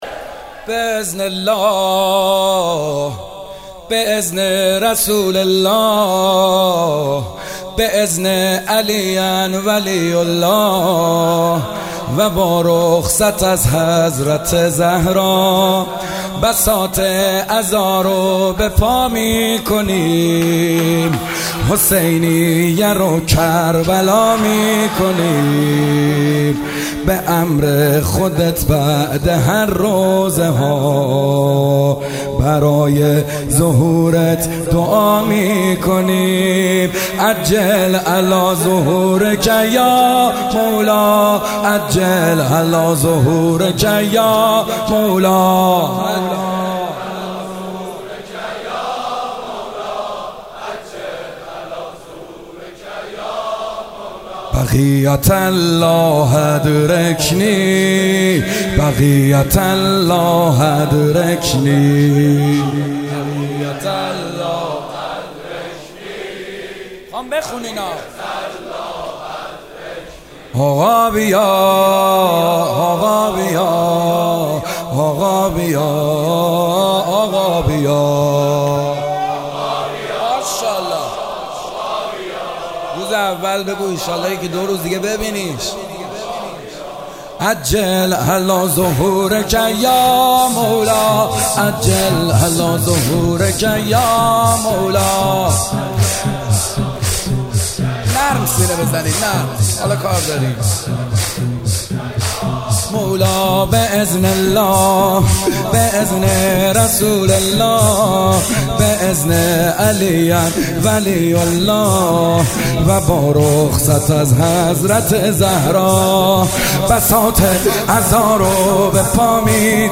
شب اول محرم97 - شور - به اذن الله به اذن رسول الله